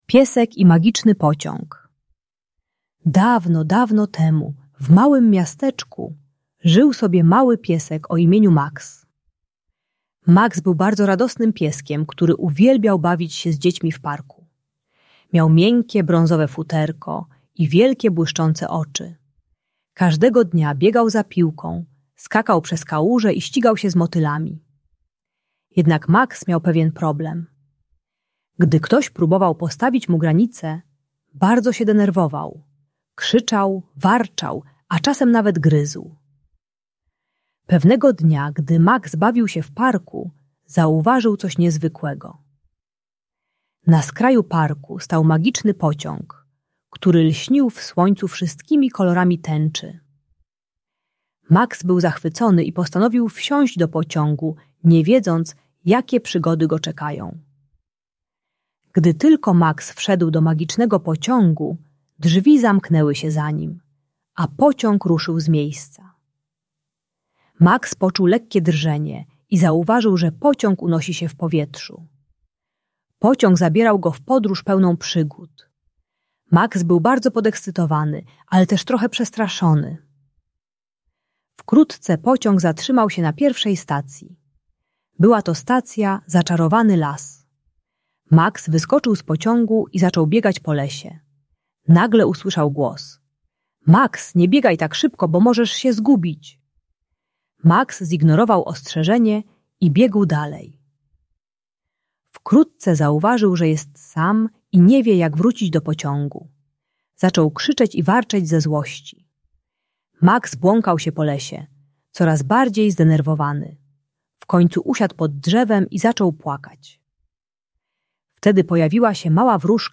Piesek Max i Magiczny Pociąg - Bunt i wybuchy złości | Audiobajka